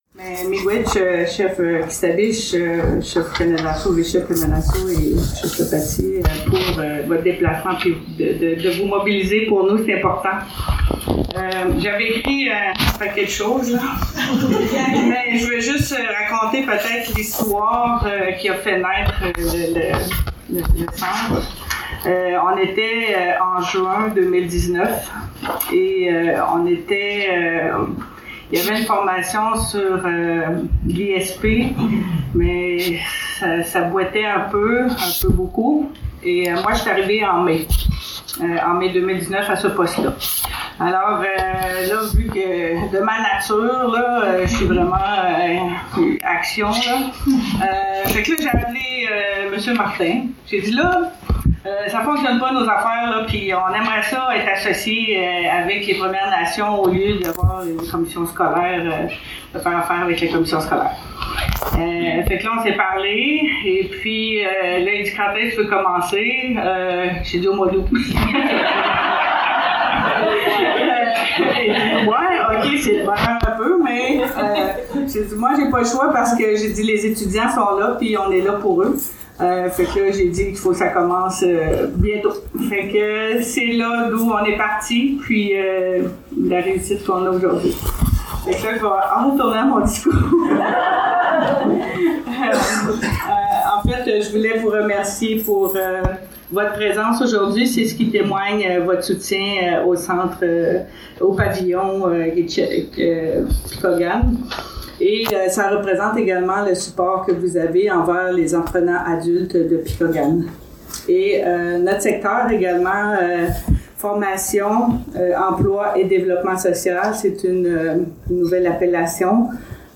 Discours